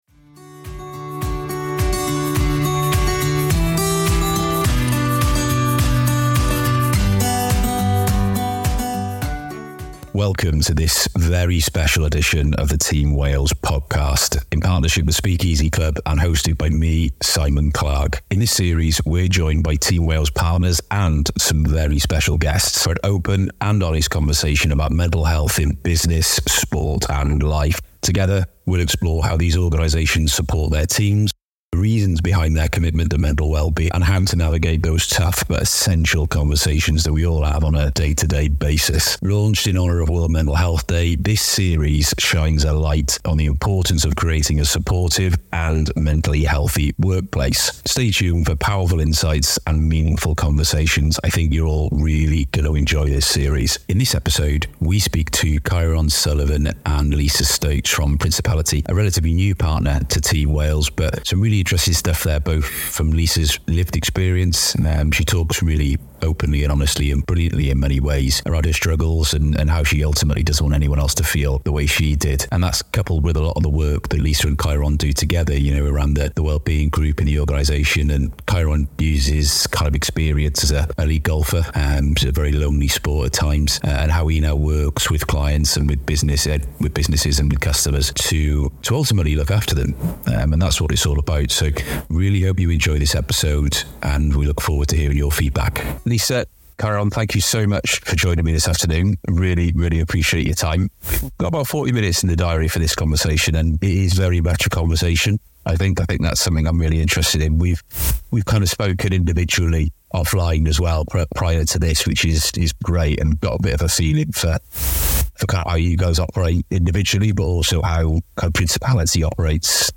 In this series, we’re joined by Team Wales partners and some very special guests for an open and honest conversation about mental health in business, sport and life. We’ll explore how these organizations support their teams, the reasons behind their commitment to mental well-being, and how to navigate those tough but essential conversations.